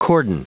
Prononciation du mot cordon en anglais (fichier audio)
Prononciation du mot : cordon